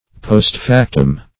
postfactum - definition of postfactum - synonyms, pronunciation, spelling from Free Dictionary Search Result for " postfactum" : The Collaborative International Dictionary of English v.0.48: Postfactum \Post`fac"tum\, n. [LL.]